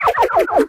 carl_no_ammo.ogg